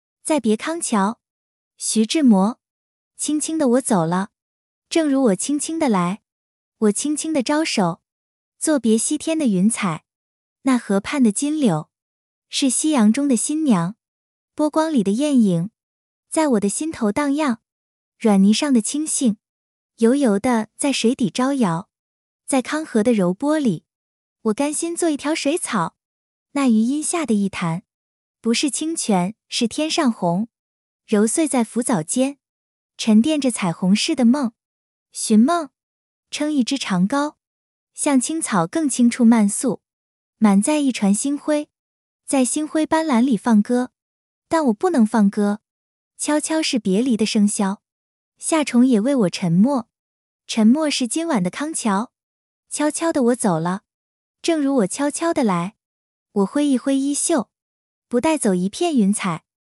晓易、云建、云霞、云阳，甚至还有讲辽宁话的小北和山西话的小妮！
做完这两步，软件就可以正确调用Xiaoxiao、Yunxi等语音包来朗读啦！
这是转录的几个语音的朗读效果：